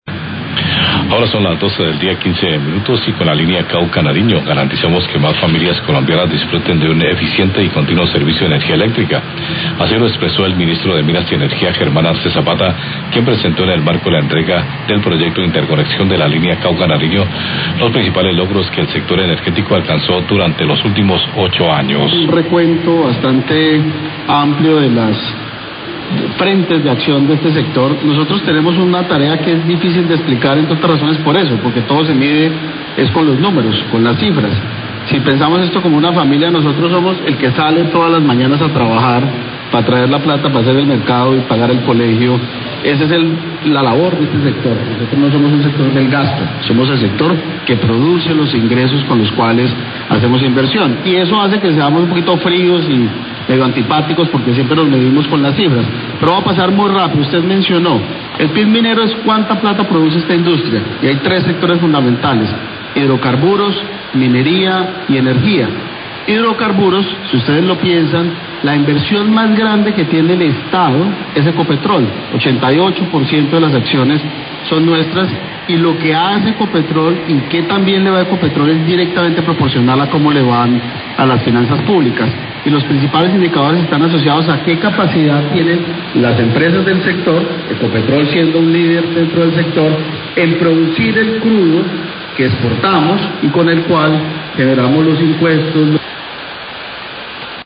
Radio
Declaraciones del Ministro de Minas y Energía, Germán Arce, sobre el balance de los principales logros del sector energético durante estos 8 años. Declaraciones en el marco de la entrega de la línea Cauca-Nariño de interconexión electrica.